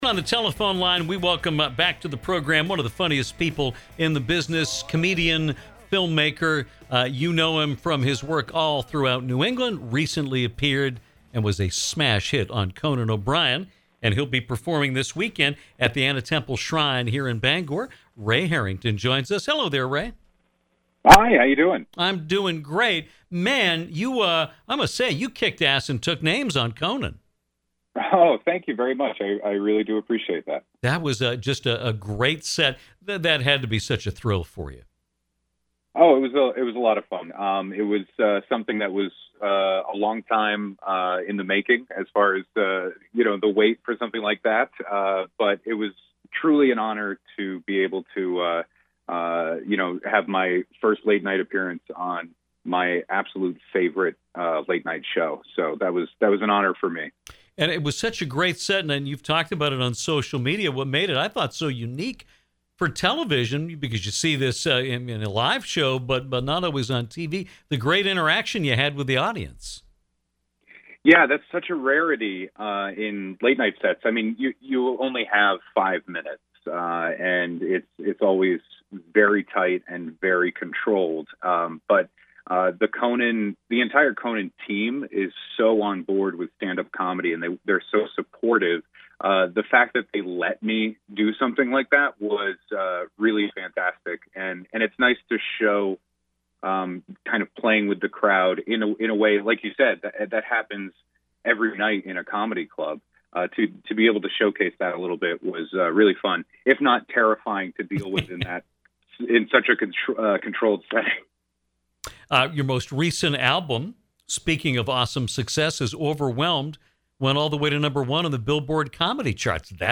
Comedian